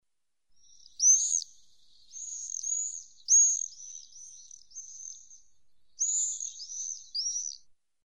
Погрузитесь в мир звуков стрижей – их звонкое щебетание напомнит о теплых летних днях.
Голос черного стрижа в небе